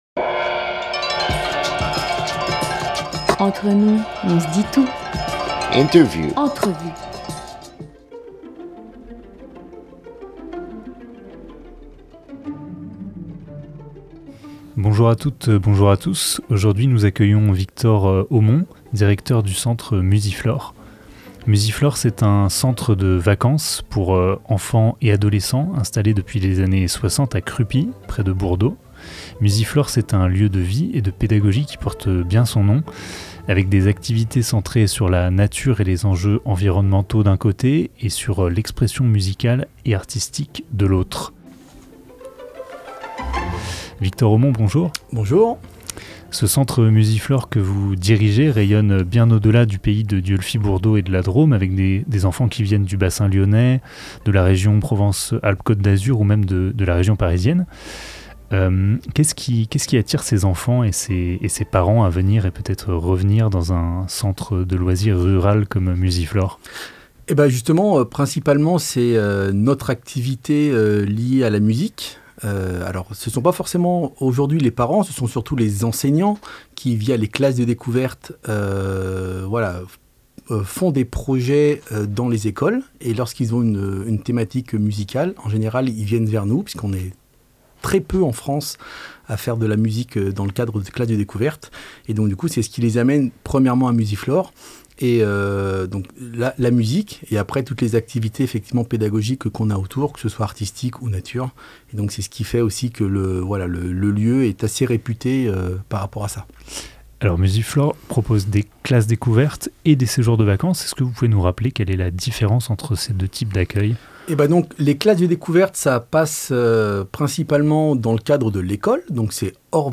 14 novembre 2022 14:04 | Interview